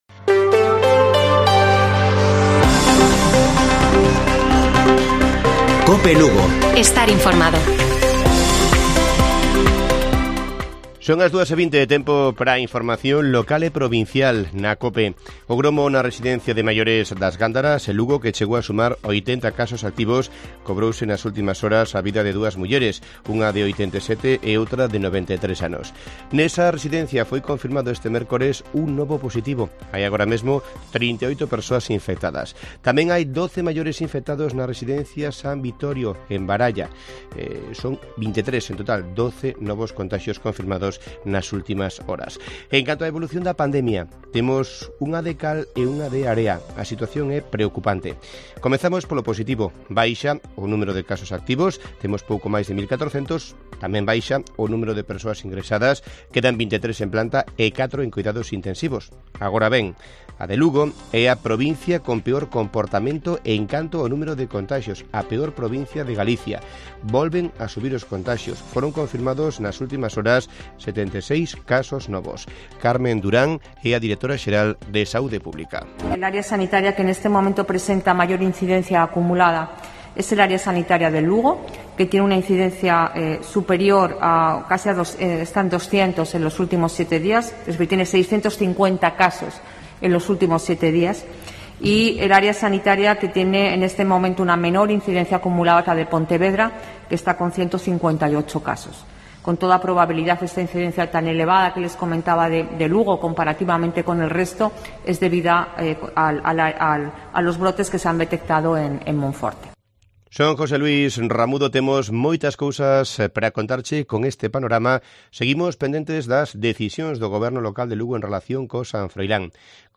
Informativo Mediodía de Cope Lugo. 01 de septiembre. 14:20 horas